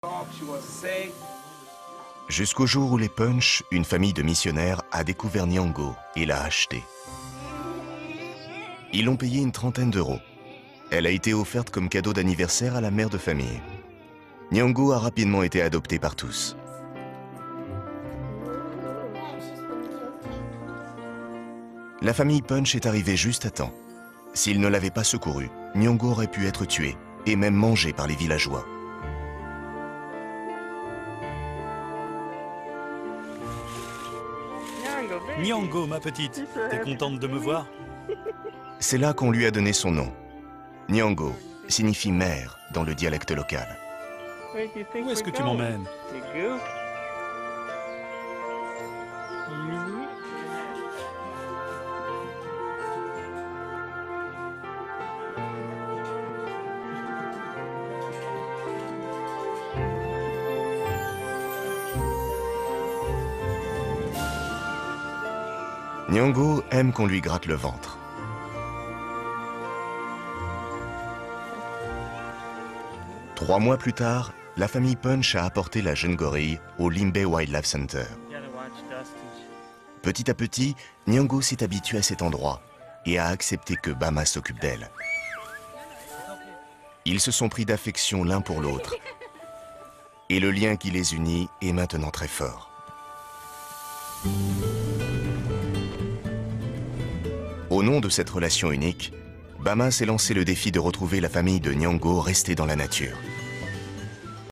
Interprétation voix-off de "Bama et les gorilles" : doux, sensible, dramatique et positif
Style sensible "happy end".
Pour « Bama et les gorilles », j’ai dû me glisser dans la peau d’un narrateur sensible, doux, posé, dramatique, positif et émotionnant.
Ma voix médium grave a été choisie pour ce projet, en raison de son timbre chaleureux et rassurant, capable de transmettre l’émotion juste.